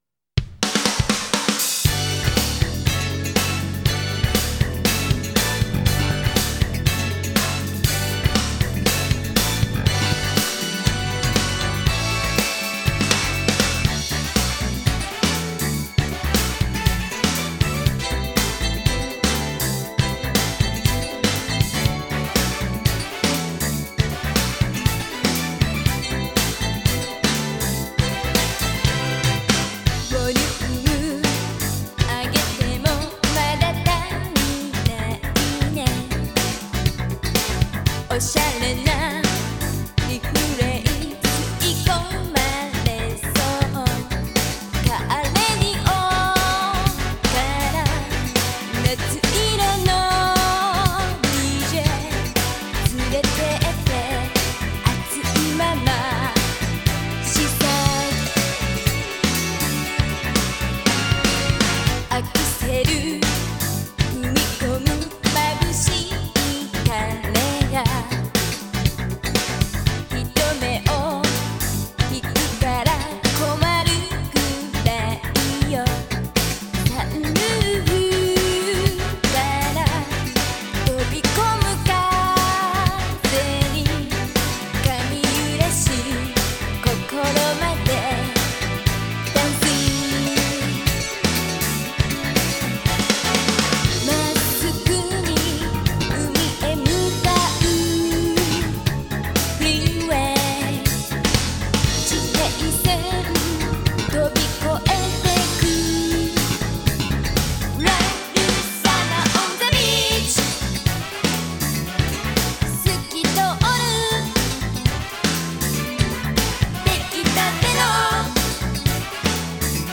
ジャンル(スタイル) JAPANESE POP / R&B